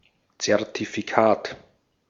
Ääntäminen
Synonyymit enregistrement papiers pièce Ääntäminen France: IPA: /dɔ.ky.mɑ̃/ Haettu sana löytyi näillä lähdekielillä: ranska Käännös Konteksti Ääninäyte Substantiivit 1.